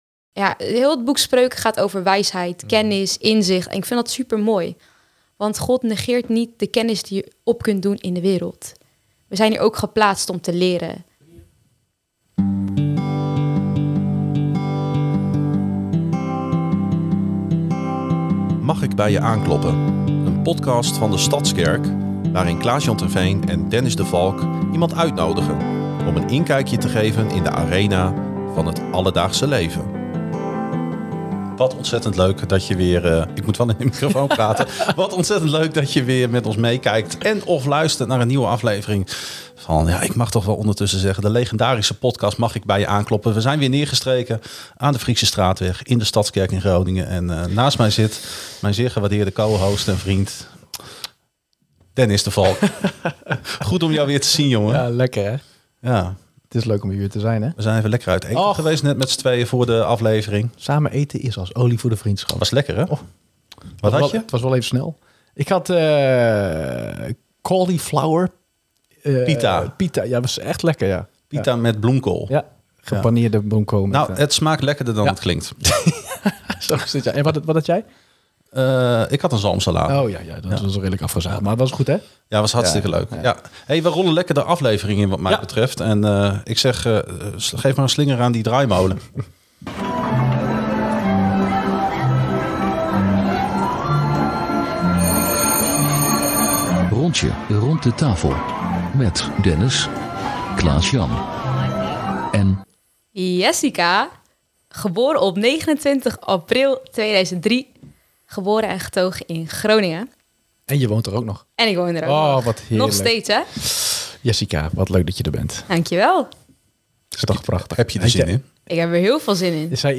De gastheren kloppen aan bij gemeenteleden en gasten om samen in gesprek te gaan.